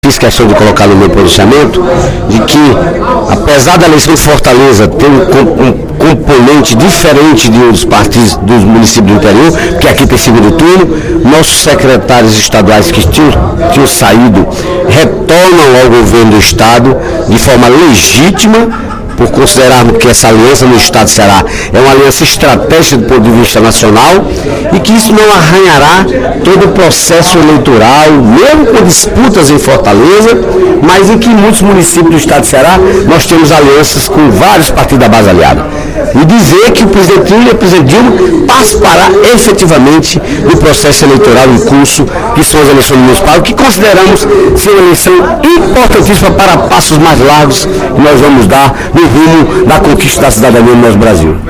Na sessão plenária da Assembleia Legislativa desta quinta-feira (05/07), o deputado Dedé Teixeira (PT) destacou a volta dos deputados petistas Nelson Martins, Camilo Santana e Professor Pinheiro aos cargos de secretários do Desenvolvimento Agrário, Cidades e Cultura, respectivamente.